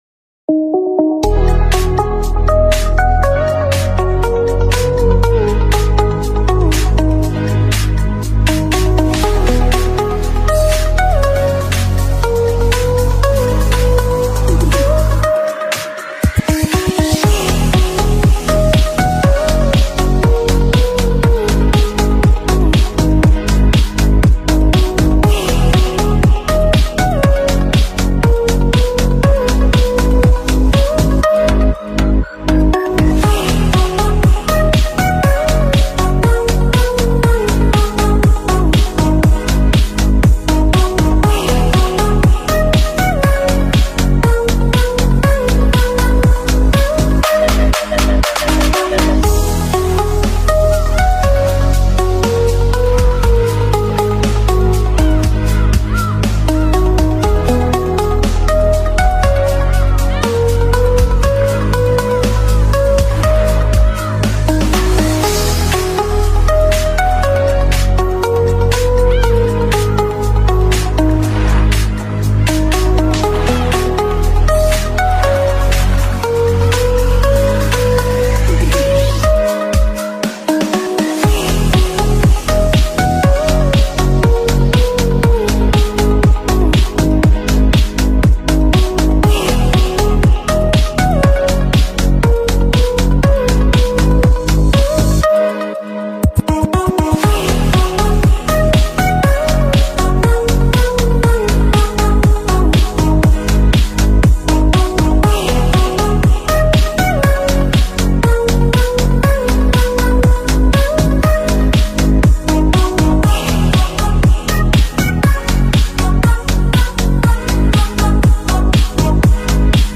kipas dinding viral sound effects free download